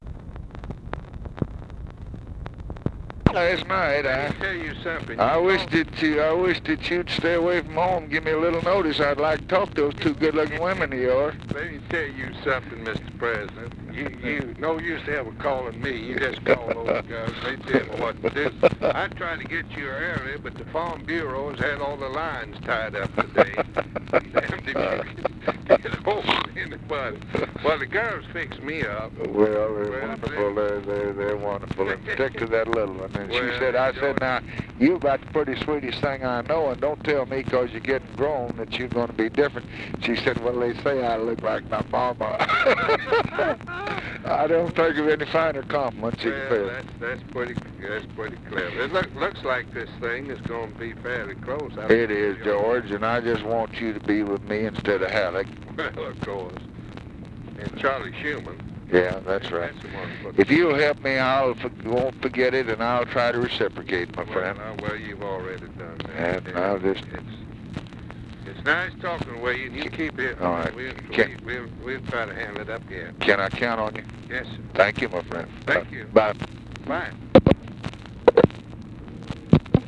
Conversation with GEORGE GRANT, April 8, 1964
Secret White House Tapes